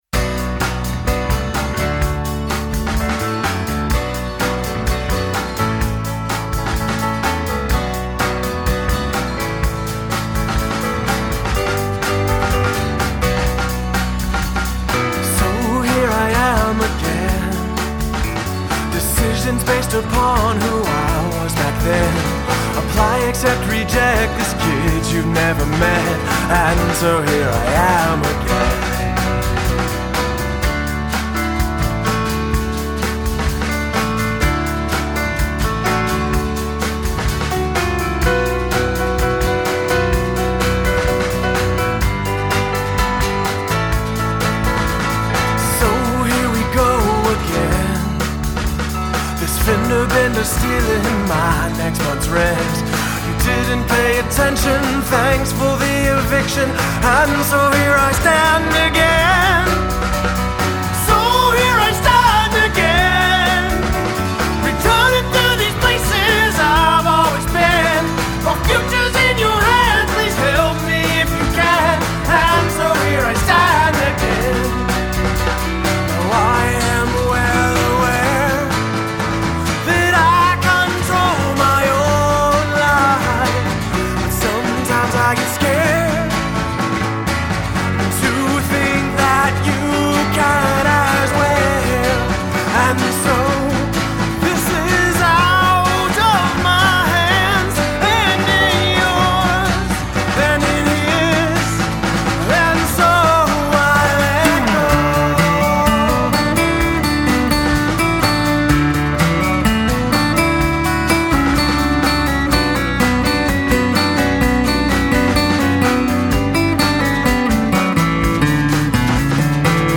Guitar, Vocals
Drums
Piano, Organ
Bass Guitar